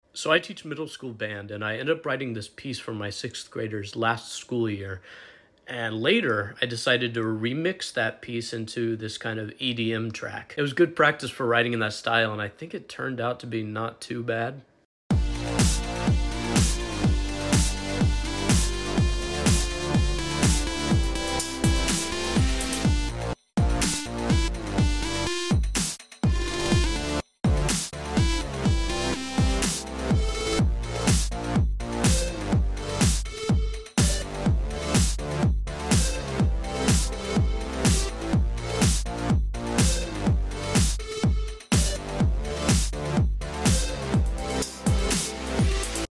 An EDM remix of my